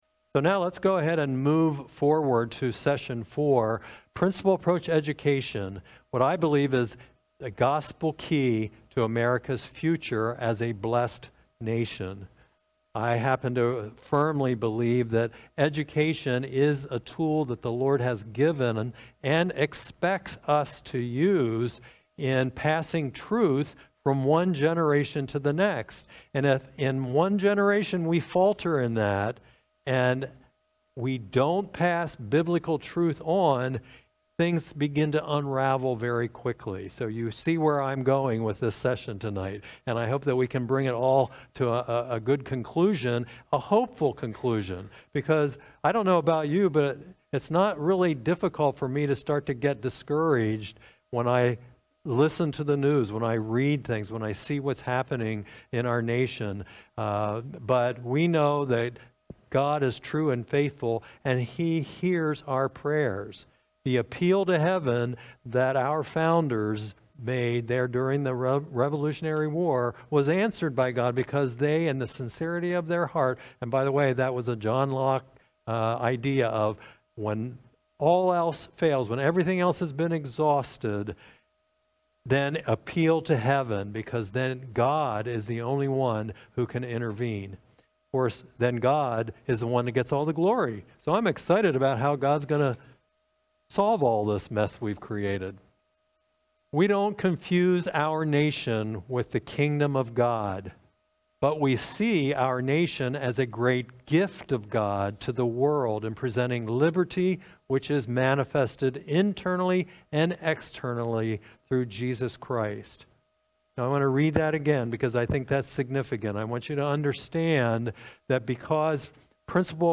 2021 Remember America Lecture Series: Does Education Matter?